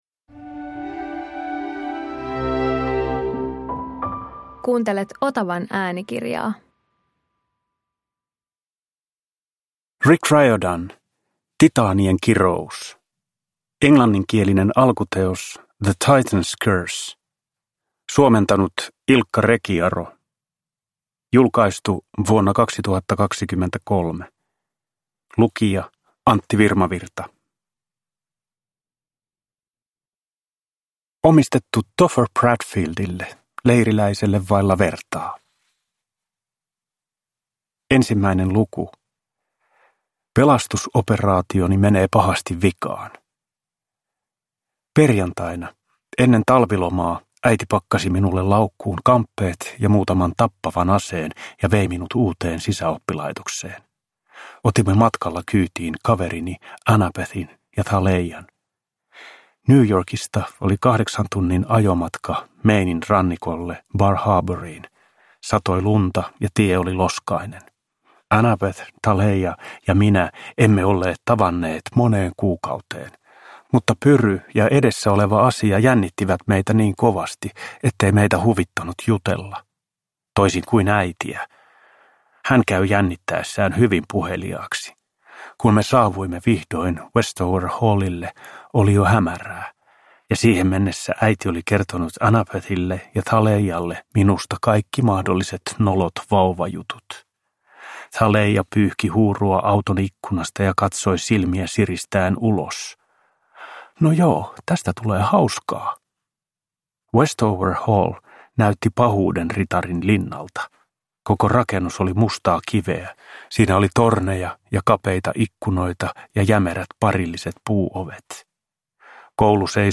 Titaanien kirous – Ljudbok – Laddas ner
Uppläsare: Antti Virmavirta